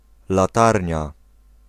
Ääntäminen
Synonyymit alkékenge Ääntäminen France (Île-de-France): IPA: /lɑ̃.tɛʁn/ France: IPA: [yn lɑ̃.tɛʁn] Paris: IPA: [lɑ̃.tɛʁn] Haettu sana löytyi näillä lähdekielillä: ranska Käännös Ääninäyte 1. latarnia {f} Suku: f .